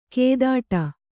♪ kēdāṭa